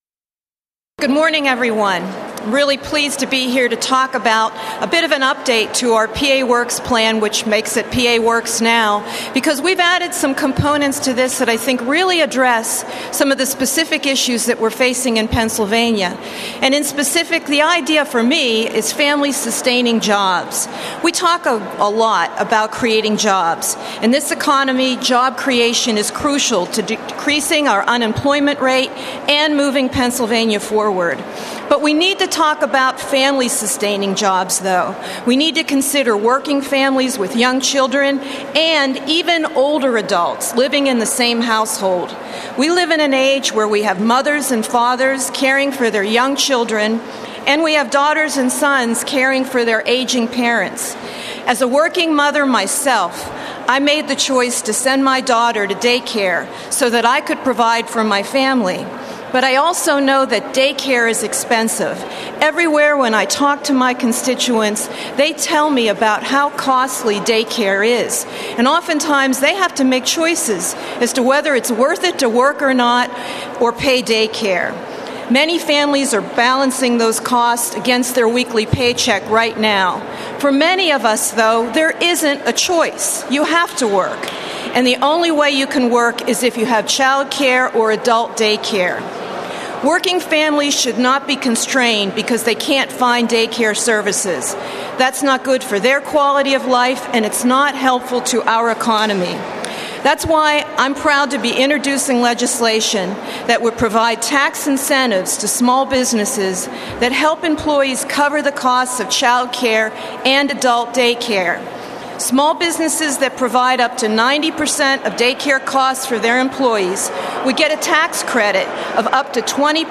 Senator Schwank on “Pa Works” Tax Credit Incentives to Encourage Day Care unveiled at State Capitol news conference today.